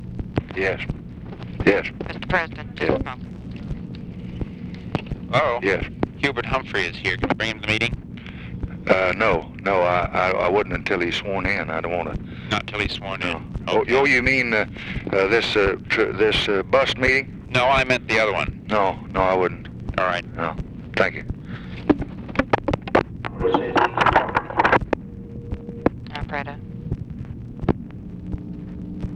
Conversation with MCGEORGE BUNDY, November 19, 1964
Secret White House Tapes